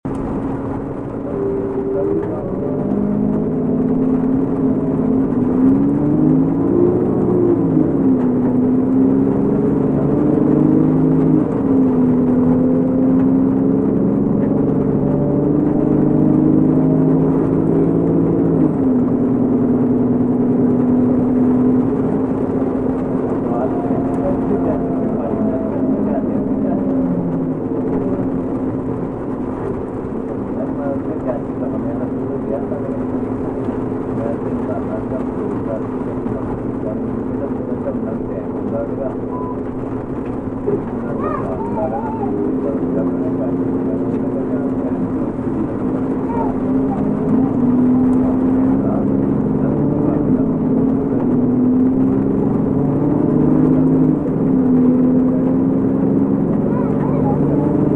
Relaxing Rainy Ride on Public sound effects free download
Relaxing Rainy Ride on Public Bus w/ People Talking Softly | Relaxing Drive at Night | Rainy Drive